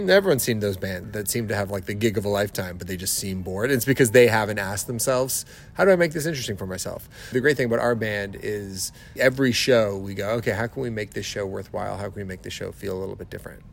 Max Kerman is the lead singer for The Arkells and spoke to Quinte News sidestage about loving live music.